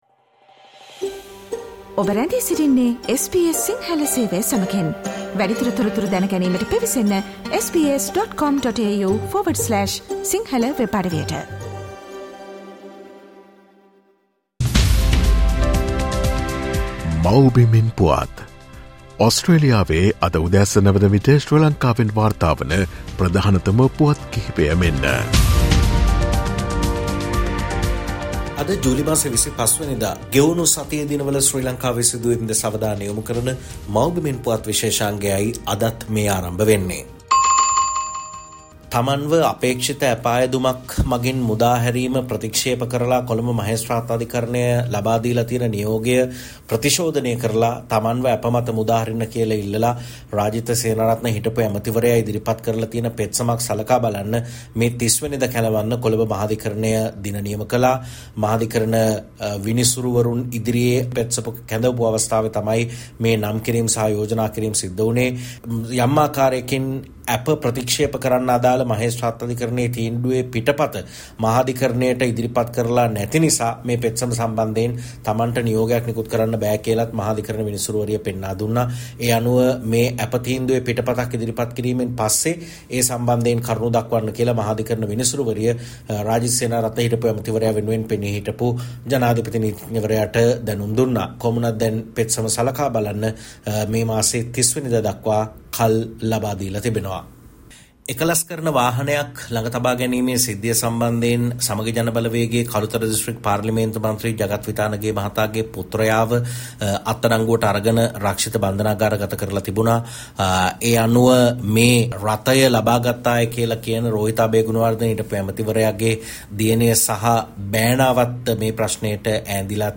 ශ්‍රී ලංකාවේ සිට වාර්තා කරයි